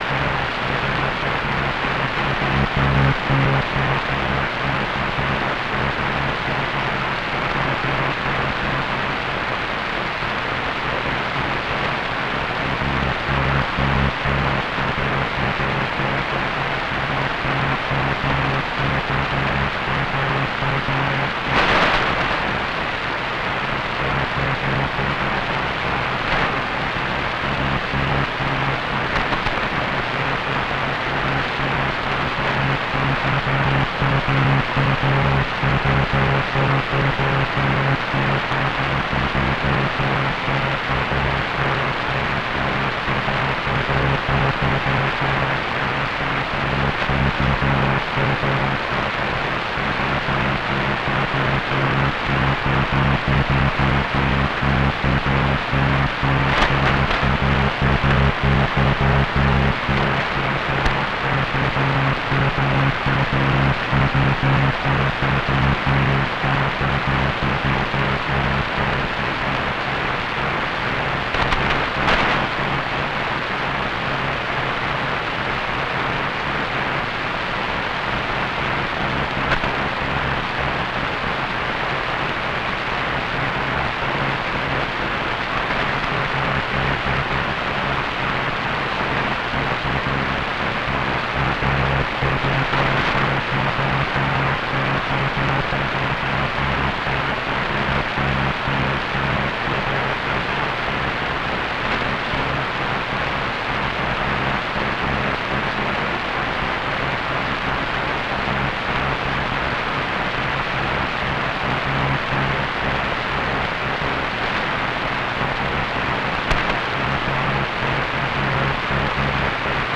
Does not sound like CW but looks like CW on the waterfall. 41 to 59.
Can also be heard on the HFU SDR, so not local to EN80.